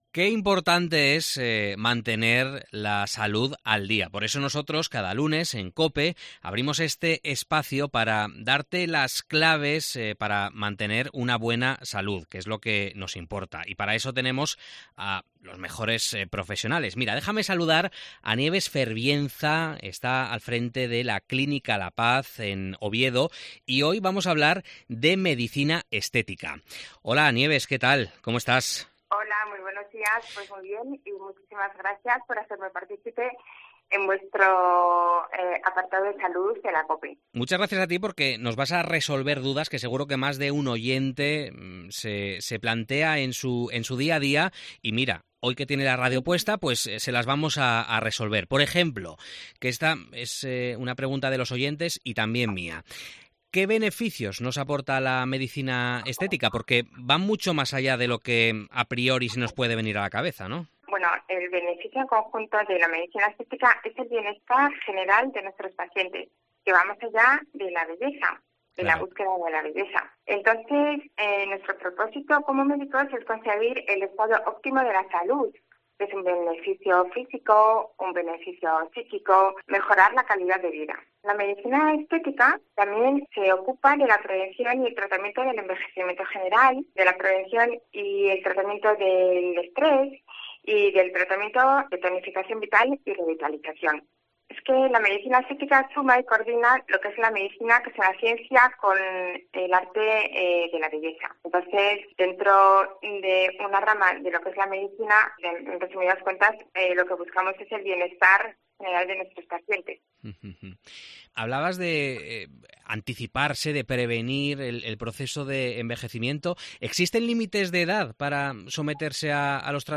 Salud al Día en COPE: entrevista